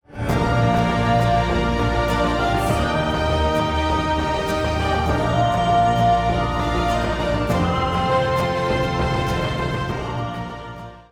wav file with vocals removed.
love_novocals.wav